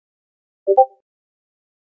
Discord Notification Sound Effect Free Download
Discord Notification